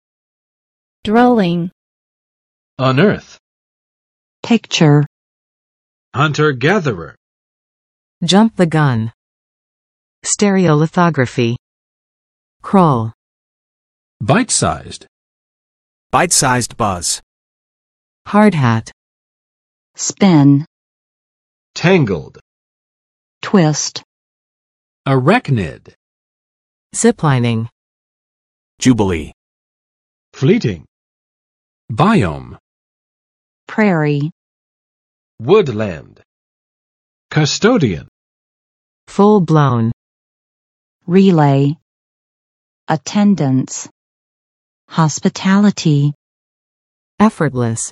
[ˋdwɛlɪŋ] n. 住处，住宅，寓所
[ʌnˋɝθ] vt.（从地下）发掘，掘出
[ˌstɛrɪəlɪˋθɑgrəfɪ] n. 立体光刻造型
[əˋræknɪd] n. 【动】蛛形纲动物